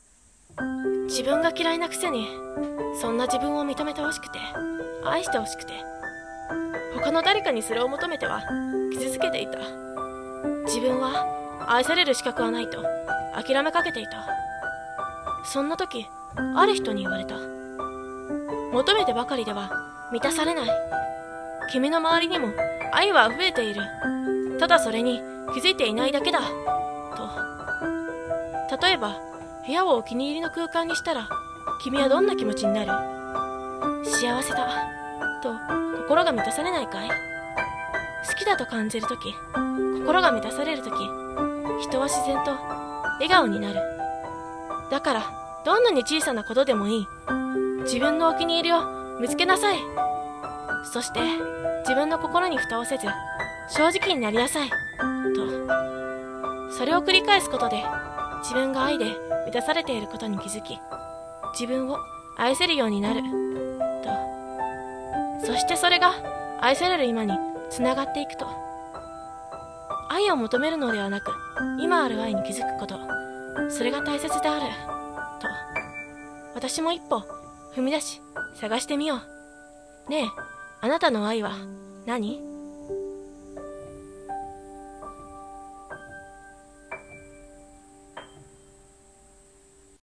【朗読】愛を探しに行こう